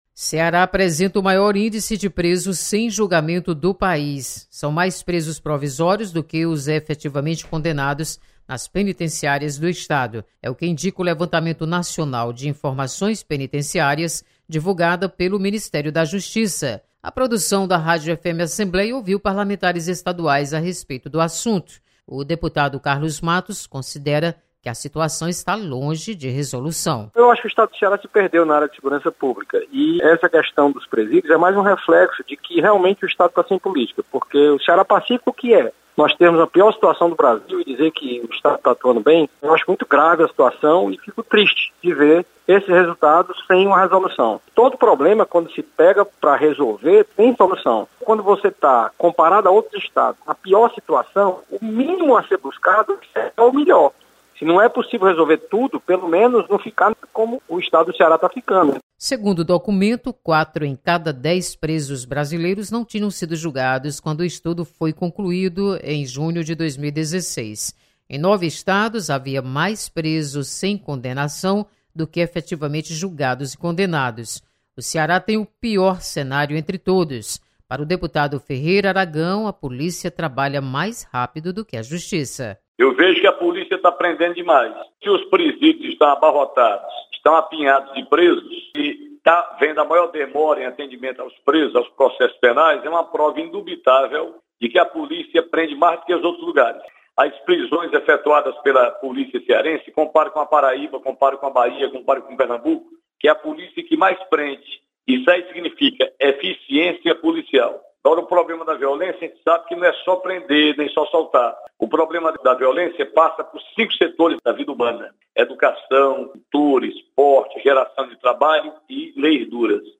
Deputados comentam sobre o número de presos sem julgamentos no  Ceará.